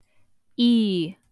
Long and short vowel sounds
long E (audio/mpeg)